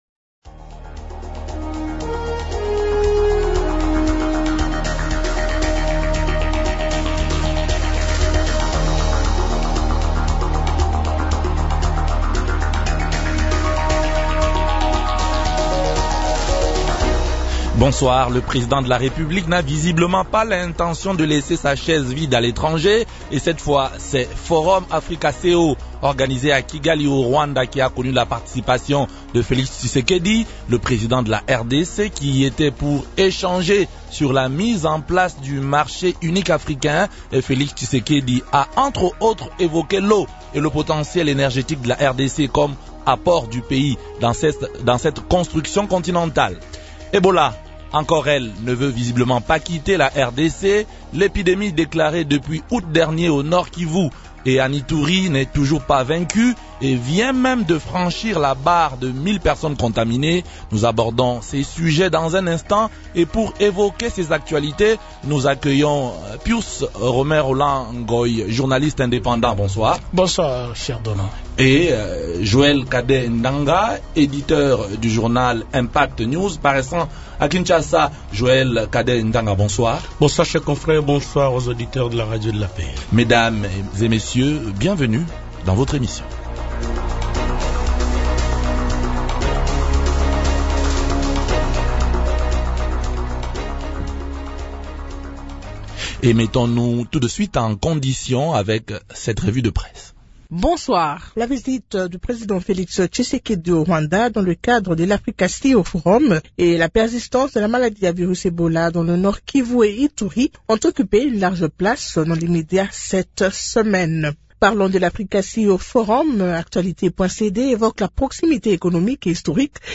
journaliste indépendant